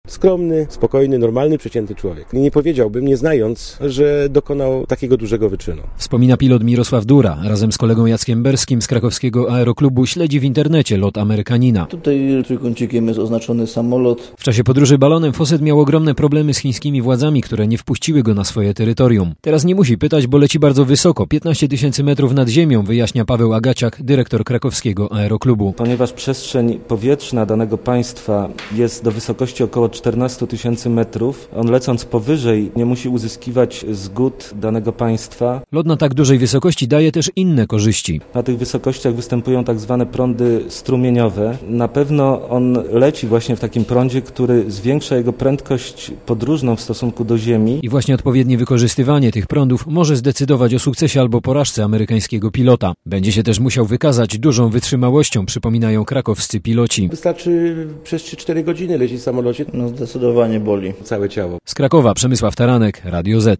Posłuchaj relacji Radia ZET Decyzję o przerwaniu bądź kontynuacji lotu podejmie ośrodek kontroli w Salinie w Kansas.